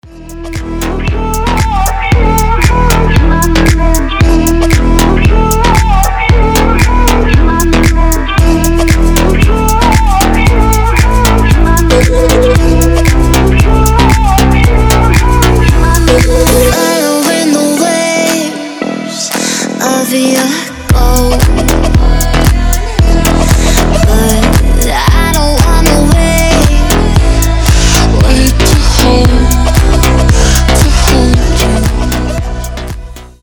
• Качество: 320, Stereo
deep house
басы
Midtempo
красивый женский голос
G-House
этнические
Chill
Нереально шикарный звук